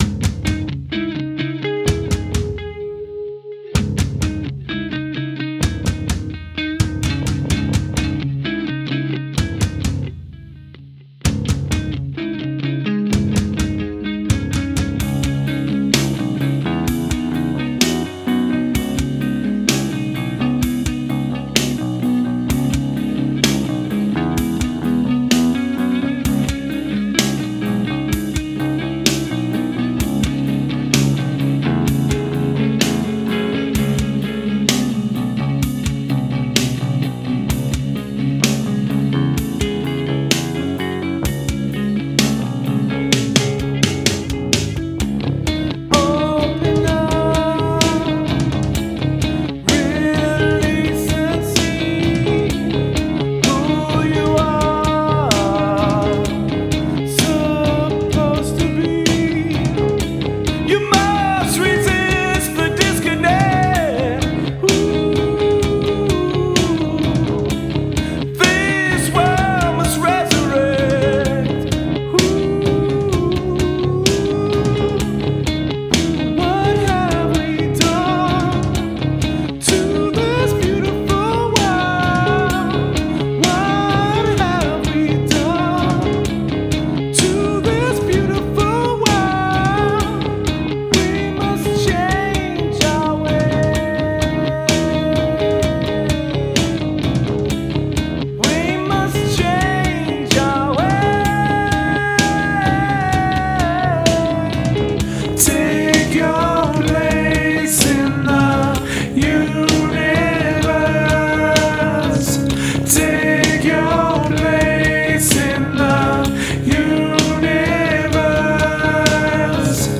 It's actually me on guitars/bass/drum programming and a vocalist/pianist, but we've been writing together for over a year, recording on our PCs in reaper.
I can't really decide what I don't like about the mix, just that I don't really like it.